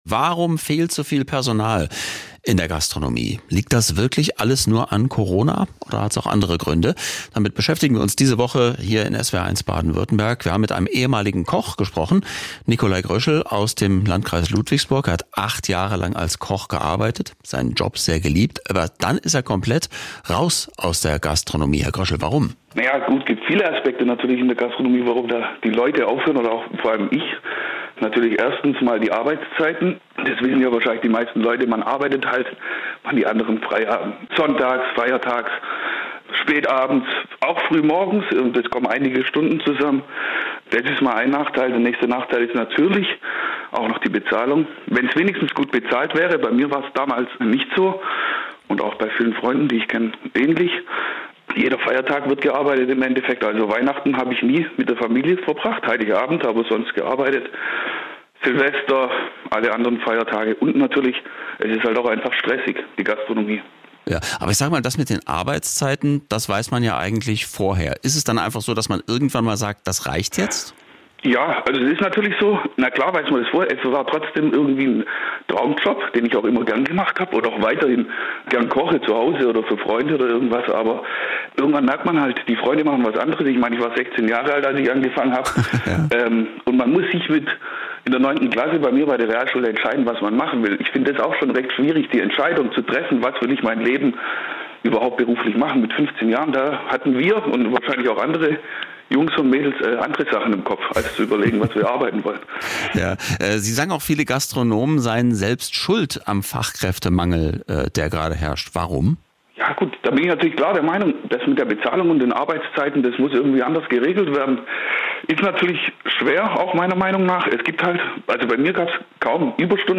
Ein Koch erzählt.